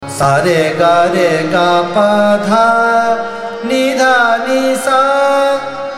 Raga
Ahlaiya Bilawal is characterized by its serene, devotional, and uplifting mood.
ArohaS R G R G P D, n D N S’